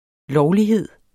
Udtale [ ˈlɒwliˌheðˀ ]